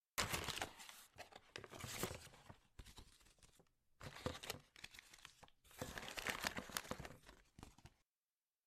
Bundle Opening Sound